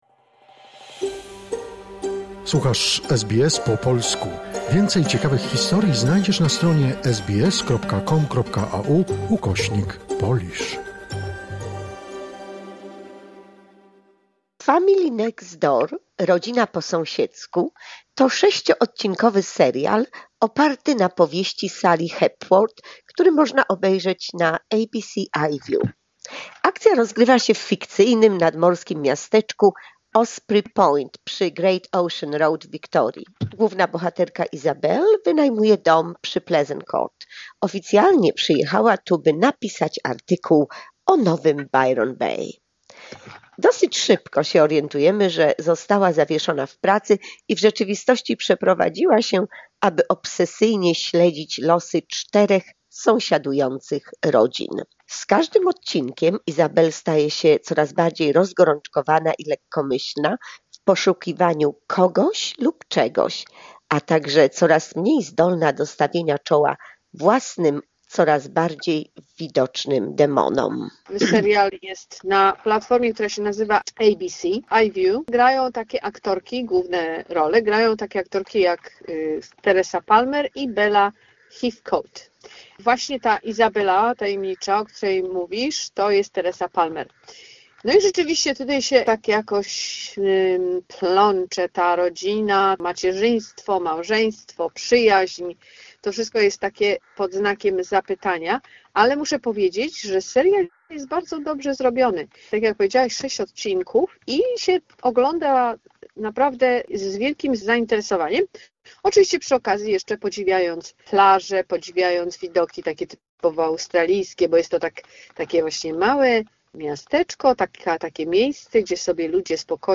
"The family next door" - recenzja filmowa